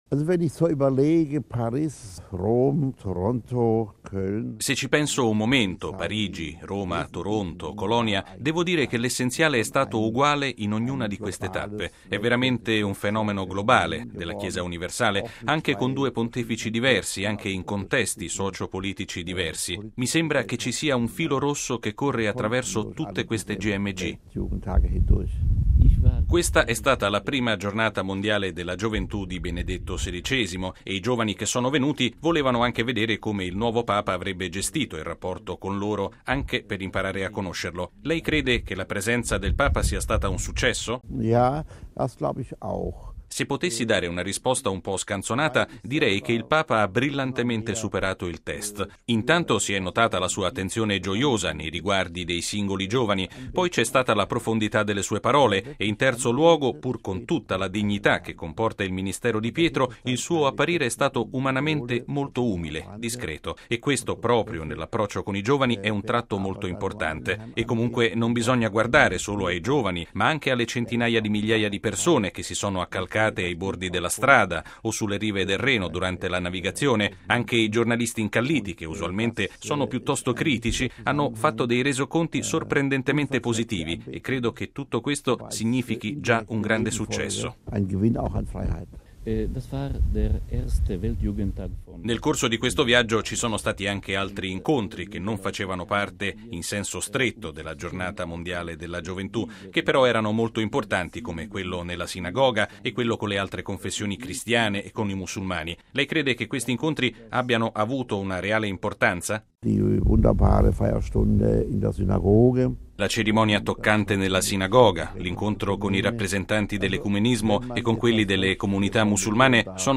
Il nostro direttore dei Programmi, padre Federico Lombardi – al seguito del Papa a Colonia - ha chiesto al porporato un bilancio dell’ultima GMG in rapporto con quelle precedenti: RealAudio